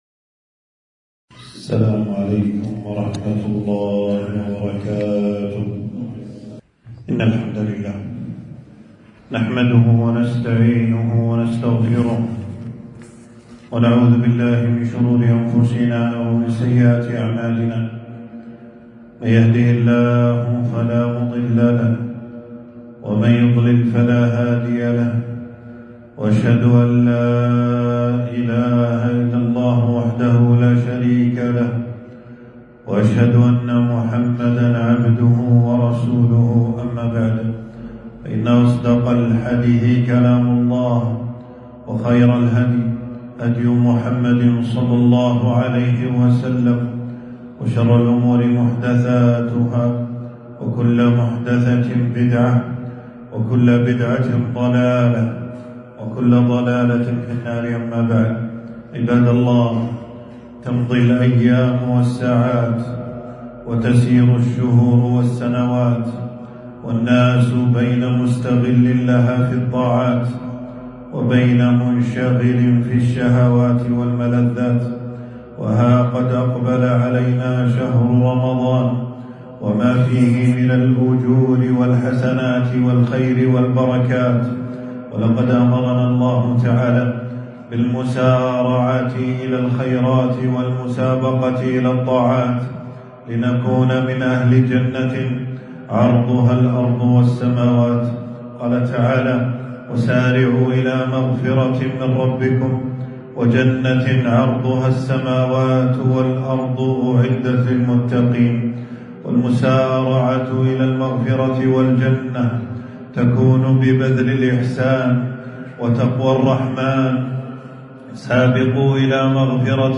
خطبة - استغلال الأوقات في شهر الخيرات